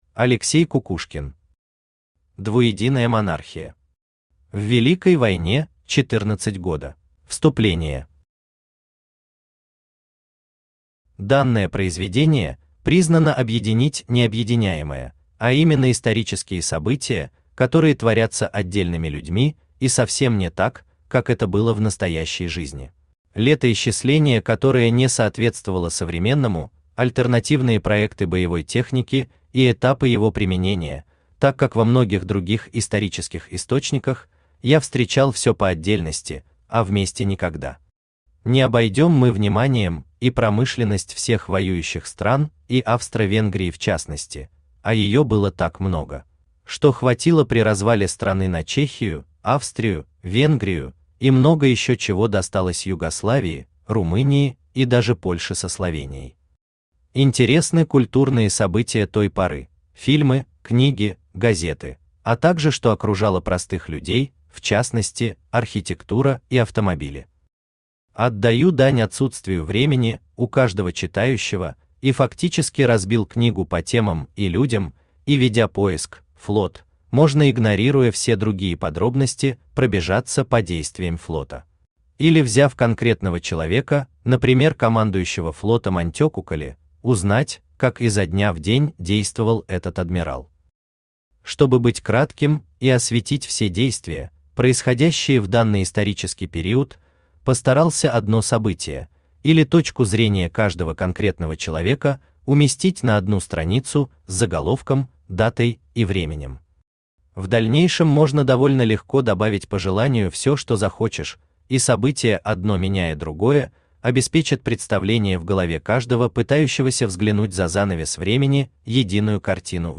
Аудиокнига Двуединая монархия. В Великой войне 14 года | Библиотека аудиокниг